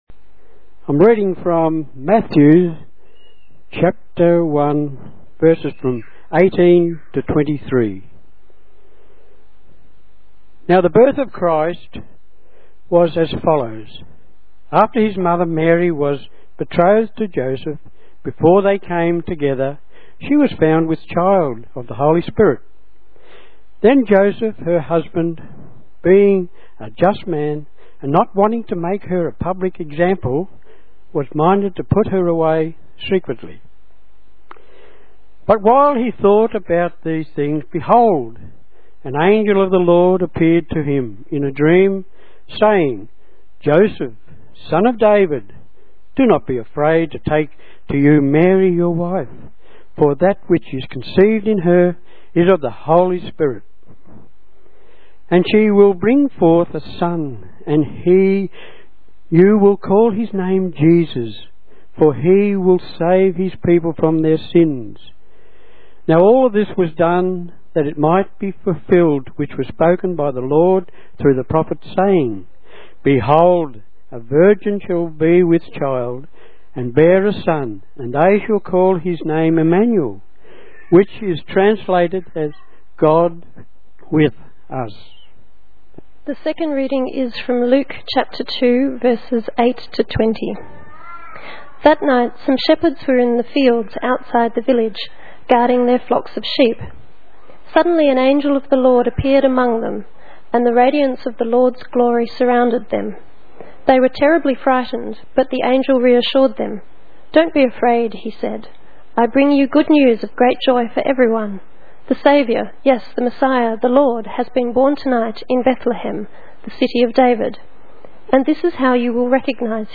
Sermon
Belief Other Readings Luke 2:8-20 View Sermon: (Size=22kb) Audio Sermon: (Size=1.2mb)